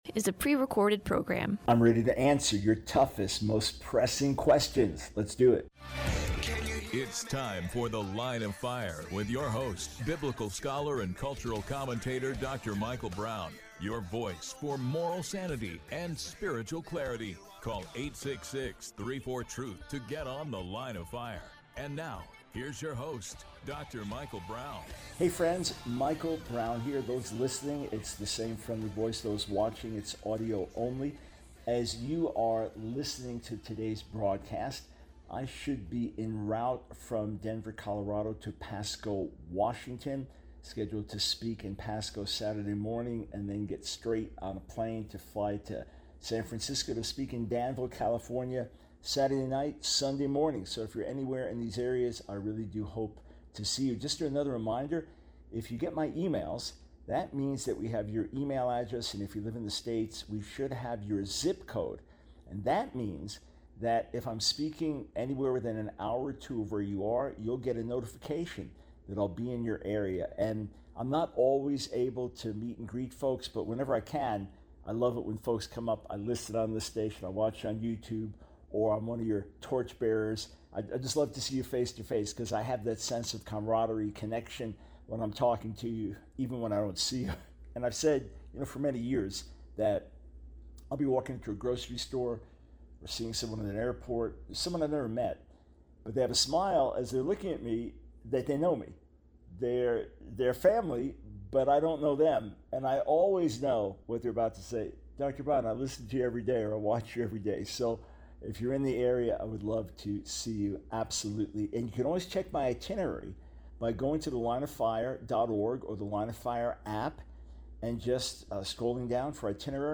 The Line of Fire Radio Broadcast for 08/16/24.